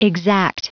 Prononciation du mot exact en anglais (fichier audio)
Prononciation du mot : exact